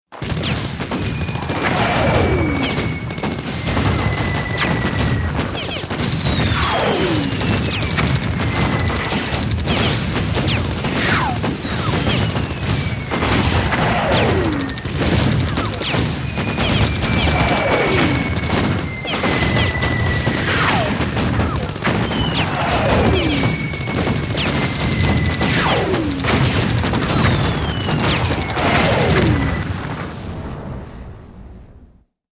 WEAPONS - VARIOUS BATTLE SCENE: EXT: Very busy & destructive battle, many guns, ricochets, bomb drops & explosions.